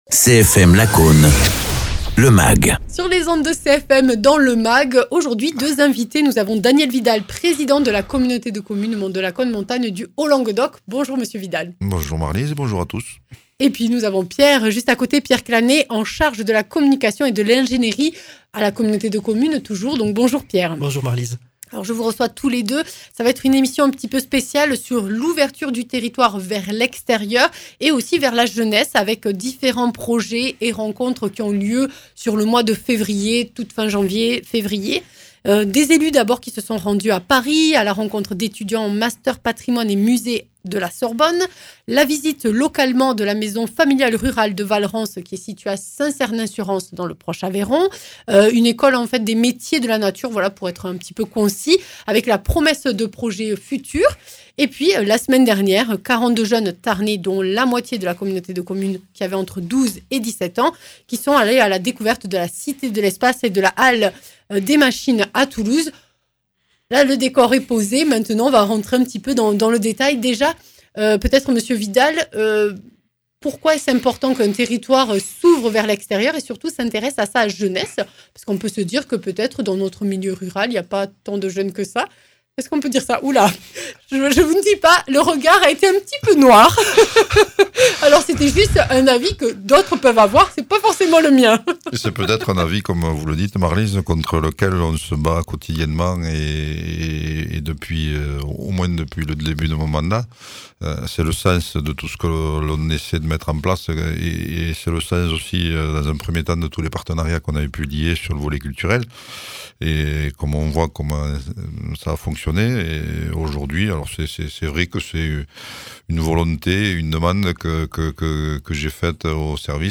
Interviews
Invité(s) : Daniel Vidal, président de la communauté de communes Monts de Lacaune, Montagne du Haut Languedoc